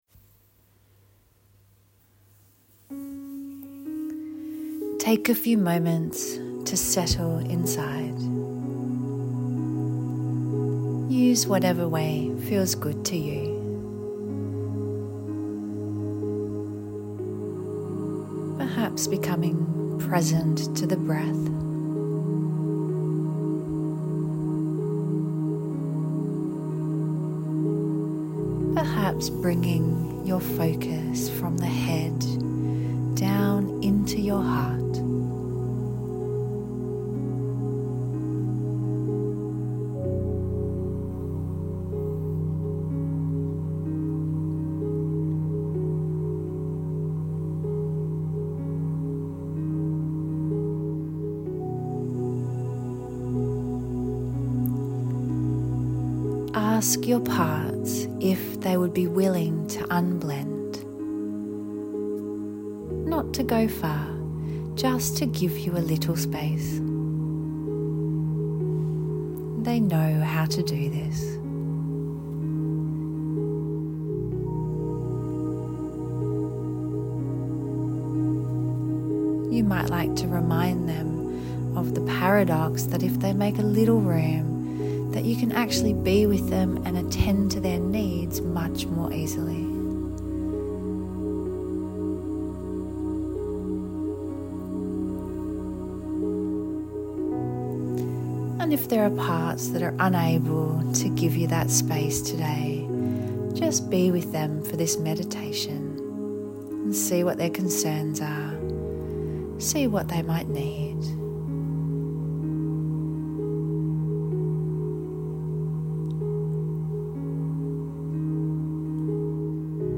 IFS-Campfire-meditation-.mp3